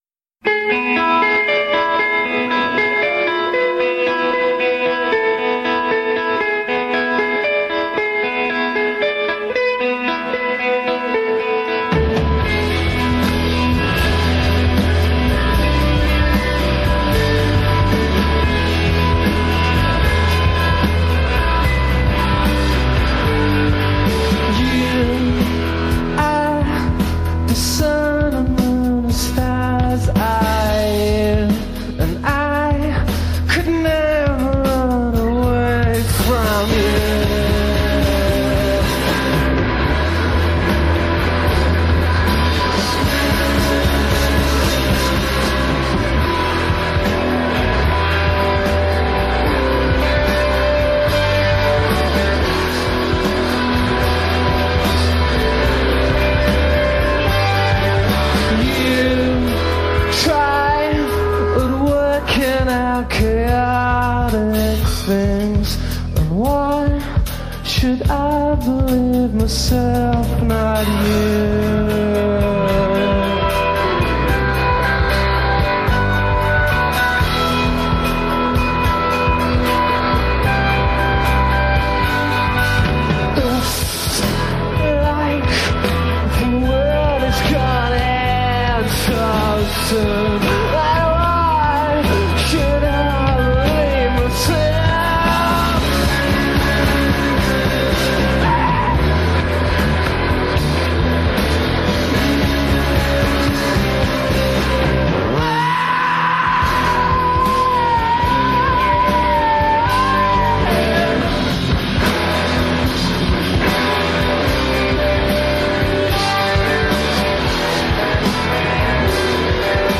in concert from Haarlem, The Netherlands